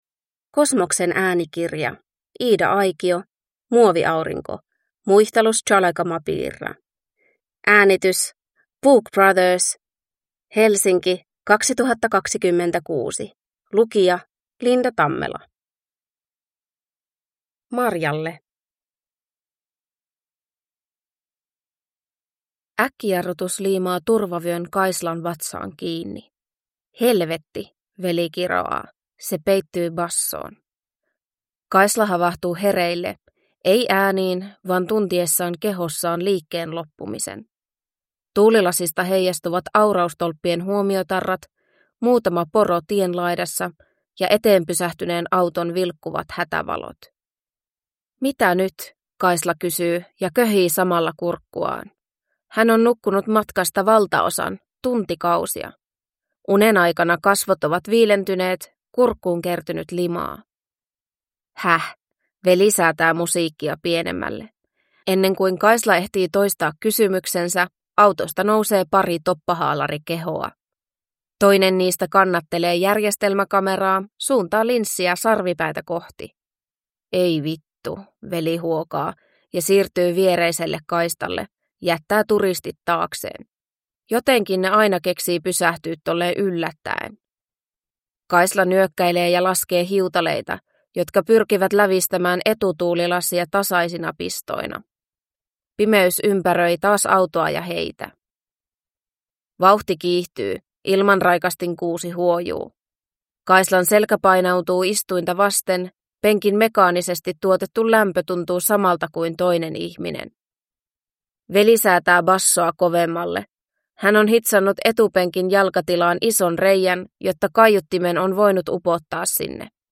Muoviaurinko – Ljudbok